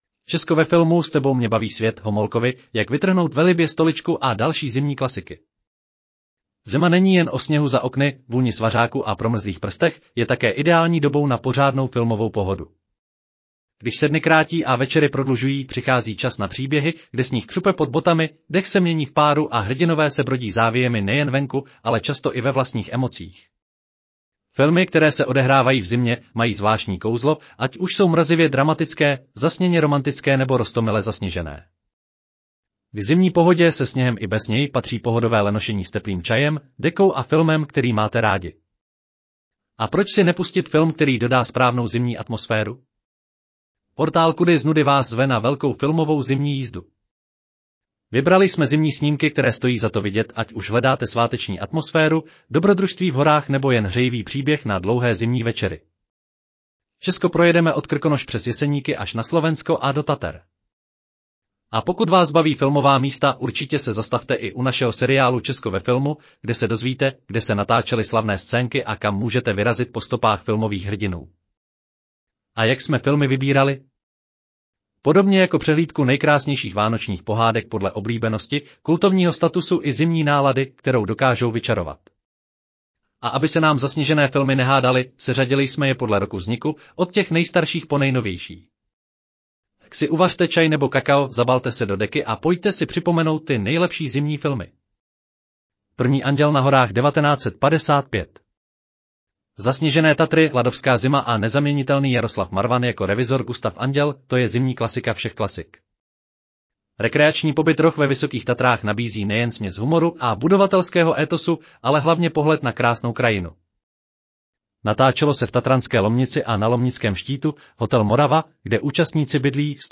Audio verze článku Česko ve filmu: Apalucha, Homolkovi a Vánice: známé i neznámé zimní filmy